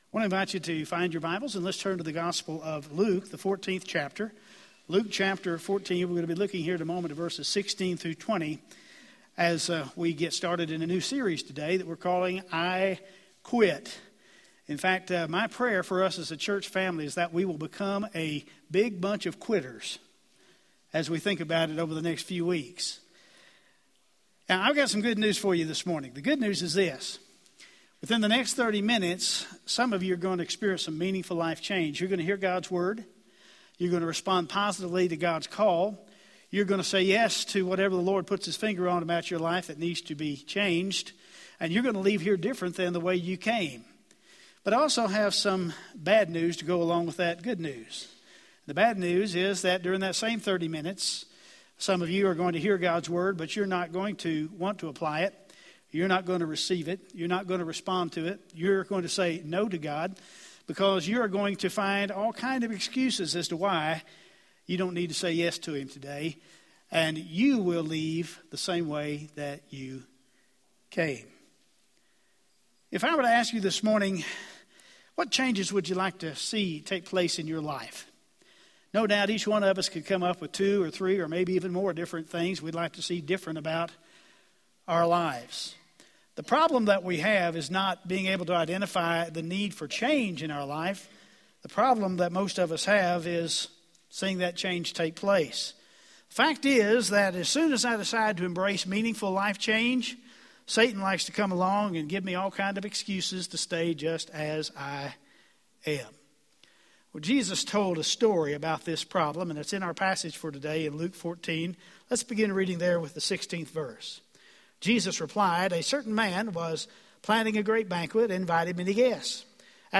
Sermons - First Baptist Portales